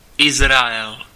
Ääntäminen
IPA : /ˈɪzreɪəl/ IPA : /ˈɪzriəl/